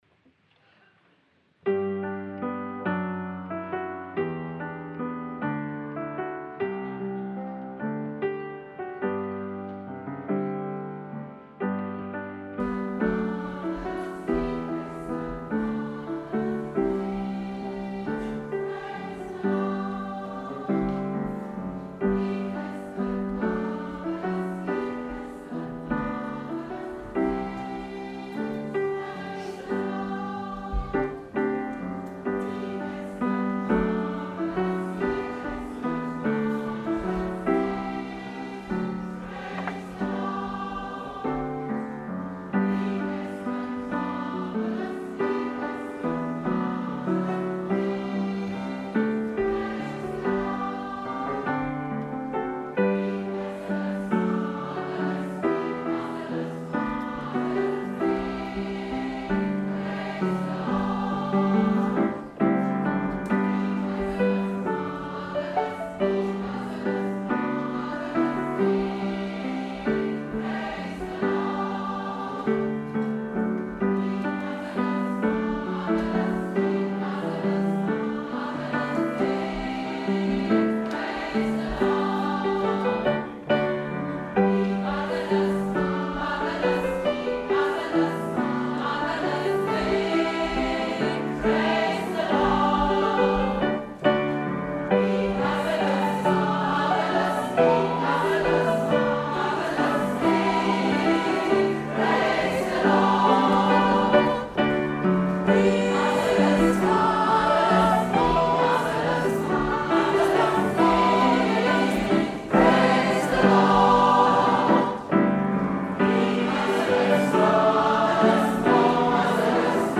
09 - Konzertaufnahmen - ChorArt zwanzigelf - Page 6
ChorArt zwanzigelf – das sind junge und jung gebliebene Sängerinnen und Sänger, die nicht nur Spaß in der Probe, sondern auch auf der Bühne haben.
Wir sind laut, leise, kraftvoll, dynamisch, frisch, modern, bunt gemischt und alles, nur nicht langweilig!